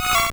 Cri de Fouinar dans Pokémon Or et Argent.